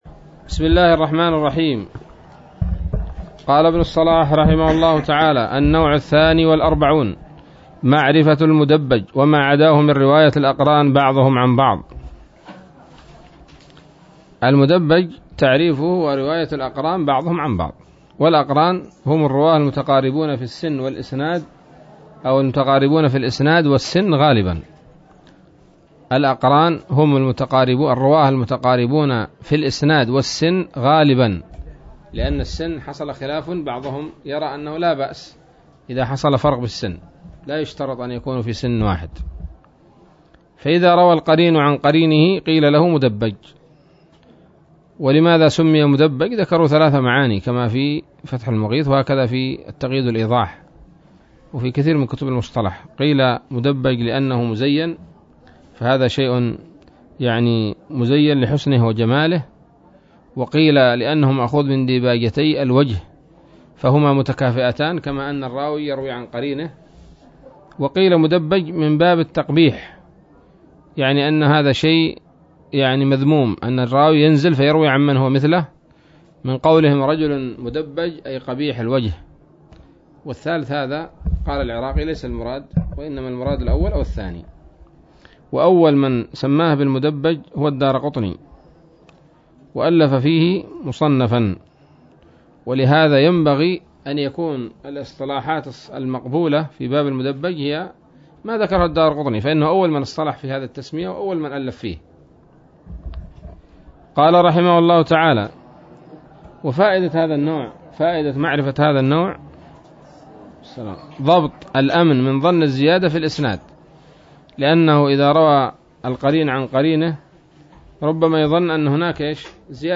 الدرس الثاني بعد المائة من مقدمة ابن الصلاح رحمه الله تعالى